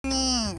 -the formant pitch is ~274 Hz
-the modulated burst is "ringing" around 4700 Hz
-the formant and harmonics have a slow downwards frequency drift, along
with short-term trills and warble